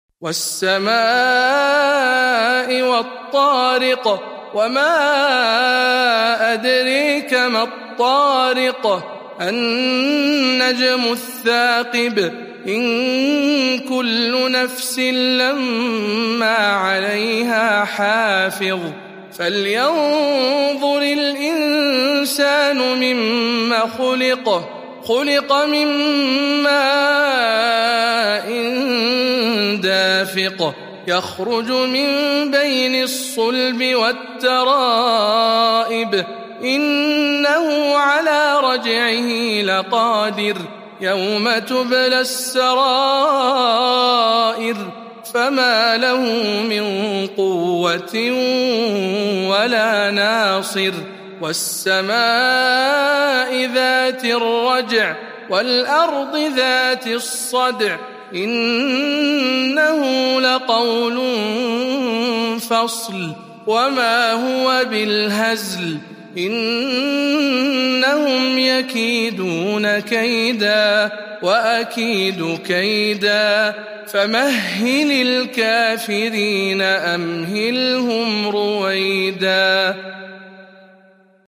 سورة الطارق برواية شعبة عن عاصم